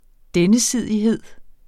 Udtale [ ˈdεnəˌsiðˀiˌheðˀ ]